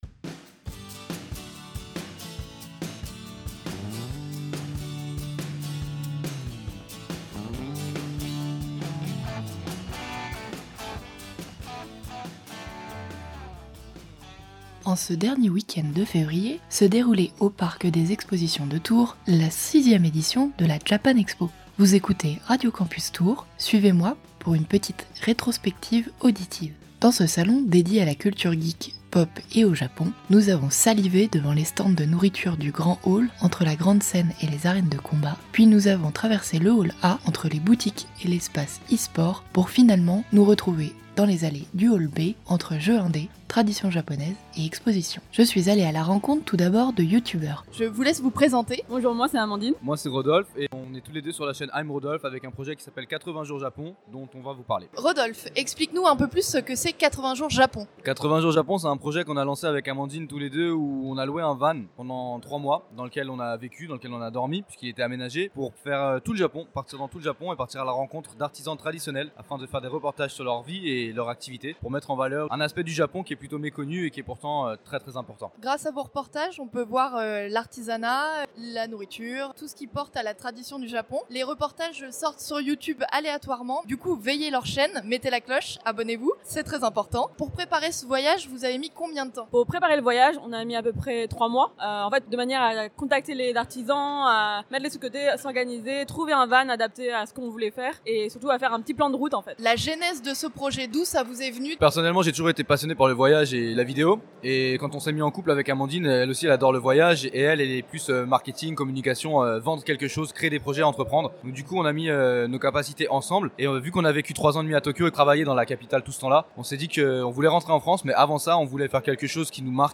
Reportage - La Japan Expo - Radio Campus Tours - 99.5 FM